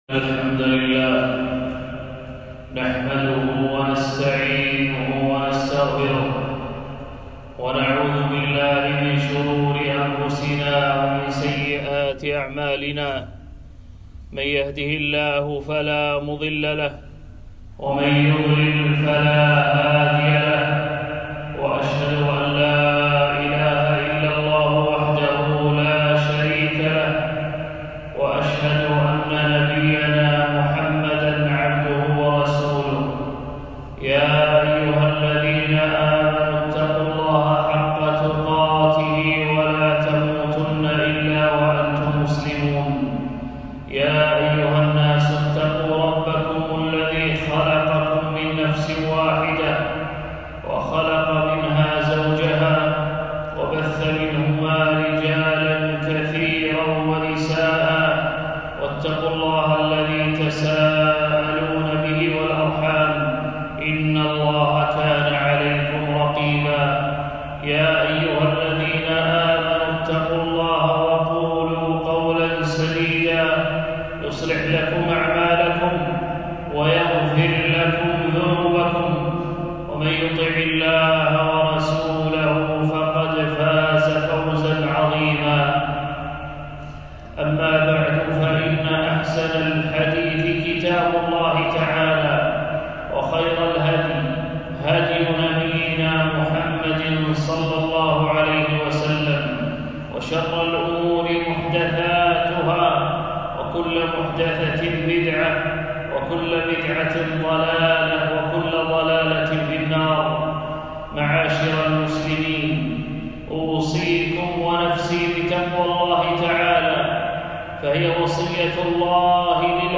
خطبة - _ فضل يوم عرفة و يوم العيد